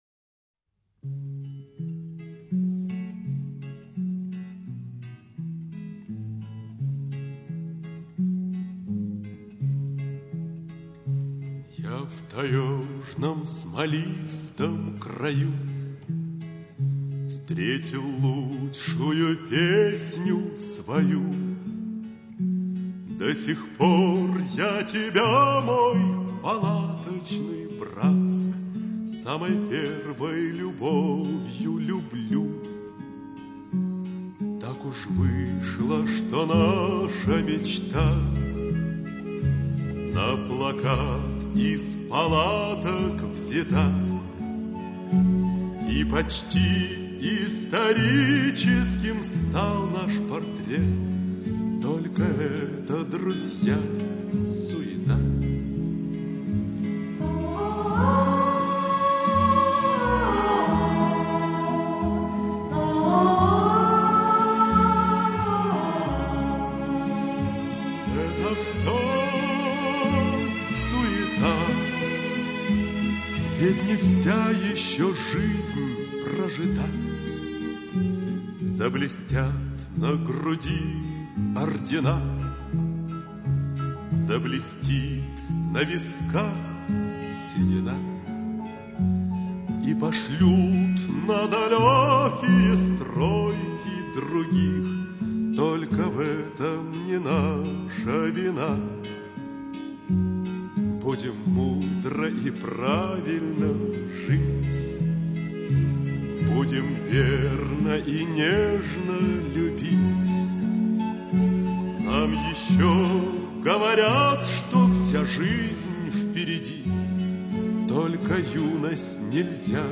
Полное грусти прощание с эпохой комсомольской романтики 60х.